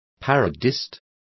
Complete with pronunciation of the translation of parodist.